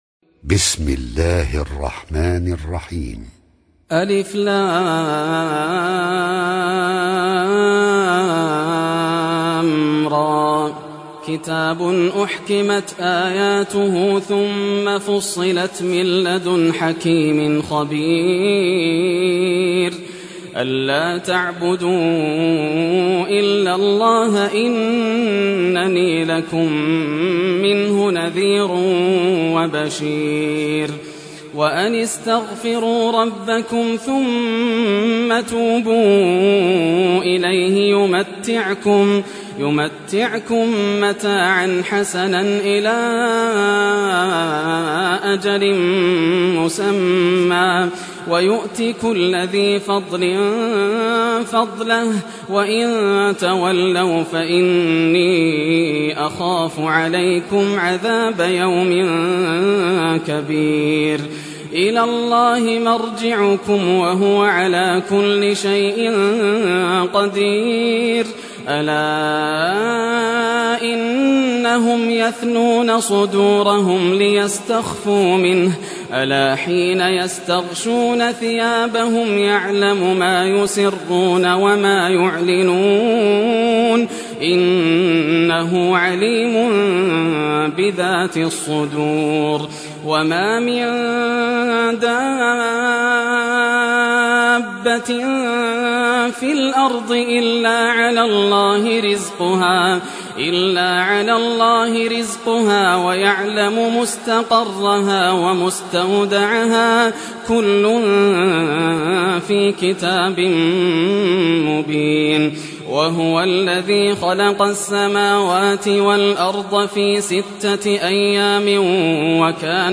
Surah Hud Recitation by Sheikh Yasser Al Dosari
Surah Hud, listen or play online mp3 tilawat / recitation in Arabic in the beautiful voice of Sheikh Yasser al Dosari.